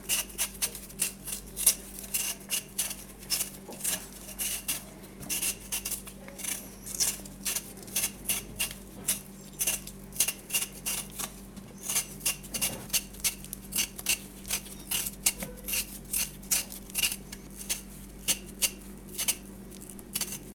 Pelar patatas
Sonidos: Acciones humanas
Sonidos: Hogar